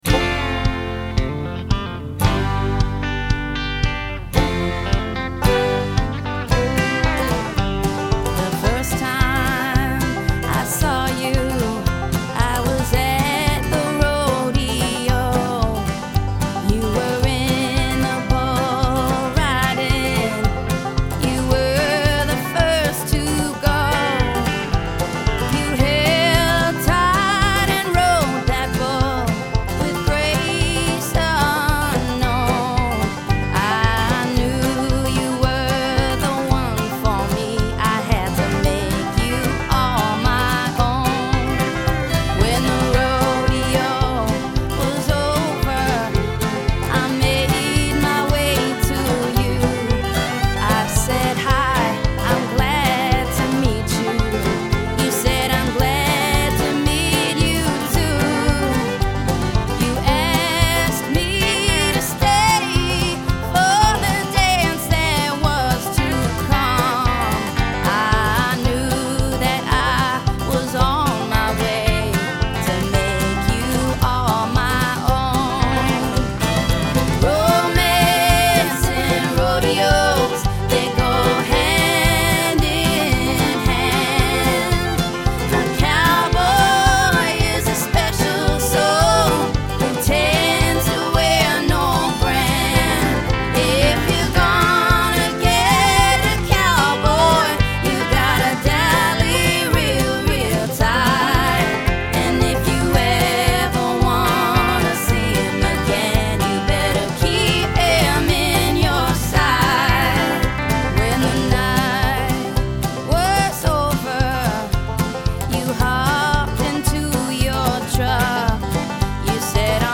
Banjo’d Up Country
country song
Pedal Steel
Fiddle